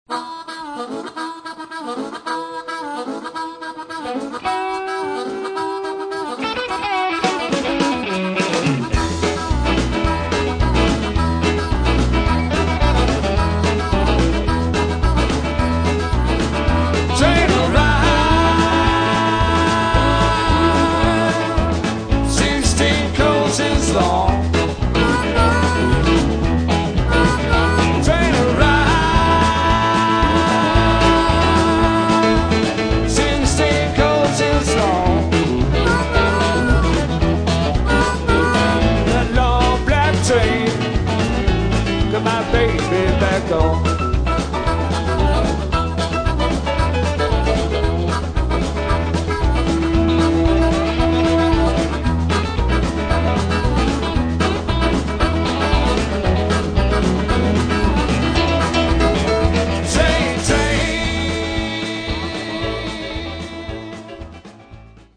sem tekinn var upp á tónleikum á Grandrokk í mars 2003.